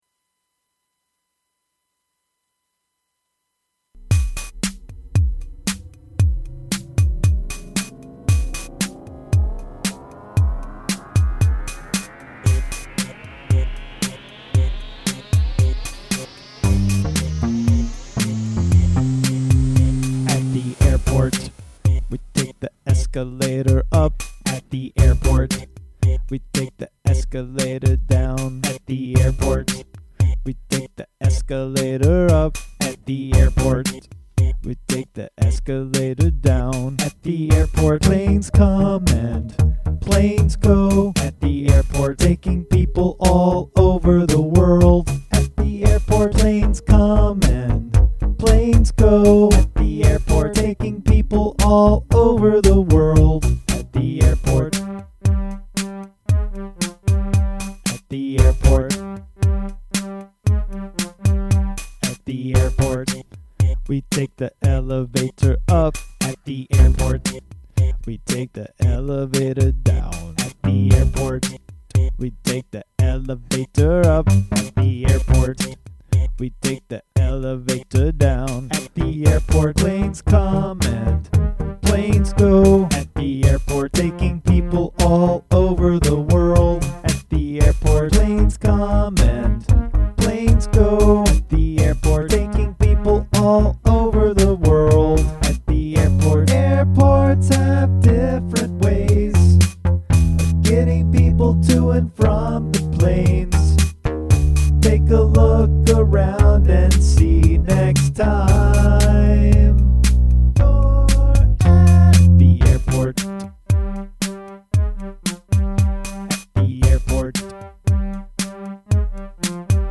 new music for kids…”Airport”
I totally admit it’s my homage to Kraftwerk disguised as a fun kid’s song.